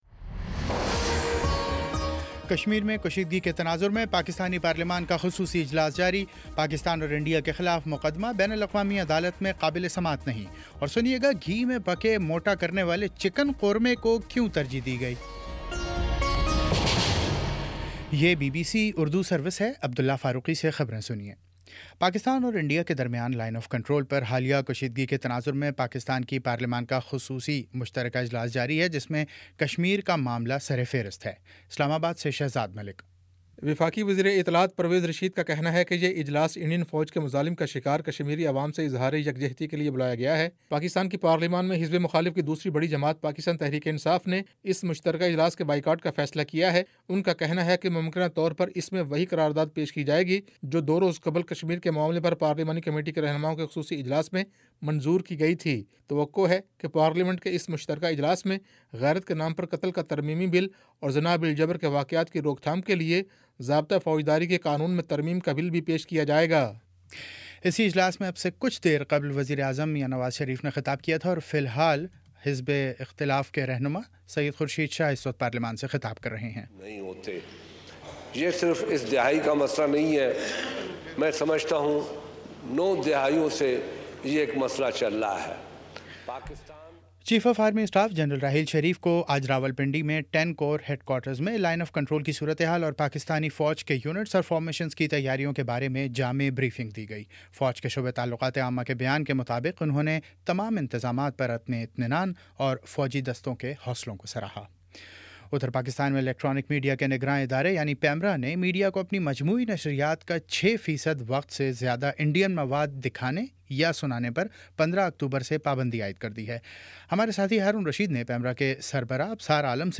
اکتوبر 05 : شام پانچ بجے کا نیوز بُلیٹن